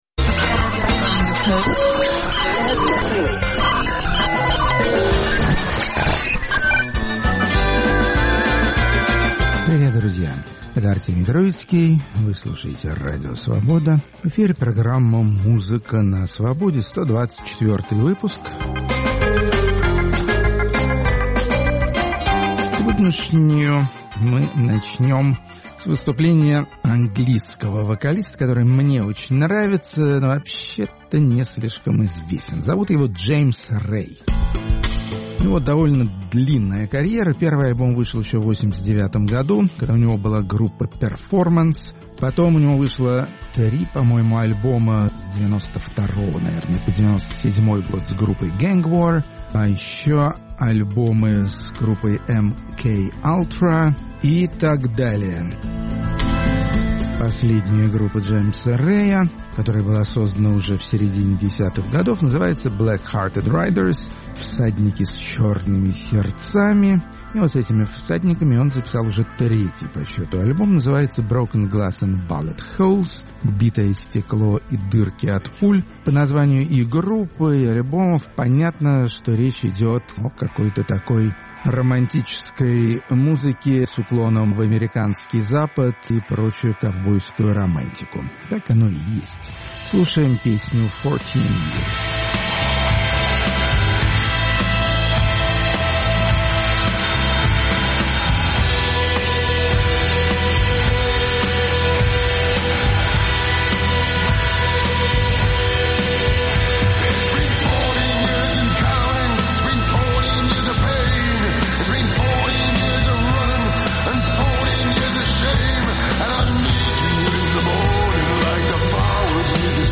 Музыка на Свободе. 28 июля, 2019 Модные израильские исполнители, работающие в самых разных жанрах современного эстрадного творчества. Рок-критик Артемий Троицкий с интересом осваивает пространства каббалистического рэпа и горного реггей-рока.